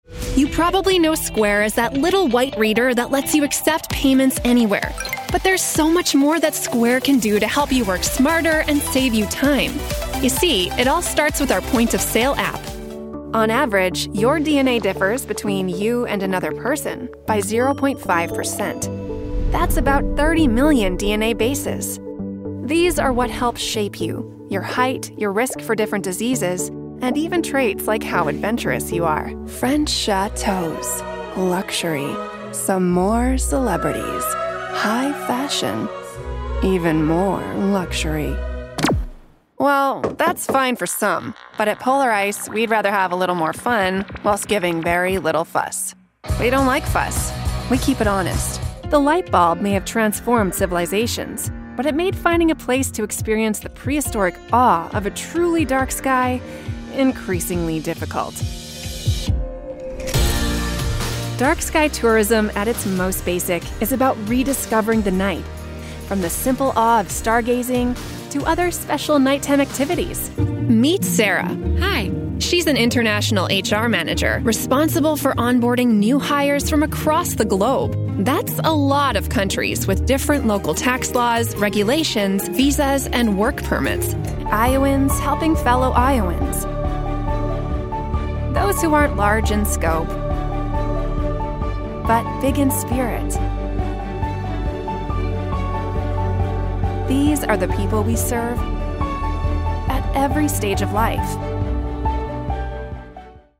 Narração
Eu uso um microfone Neumann TLM 103, uma cabine vocal com um nível de ruído de pelo menos -60dB e uma interface de áudio Steinburg UR22C.
Jovem adulto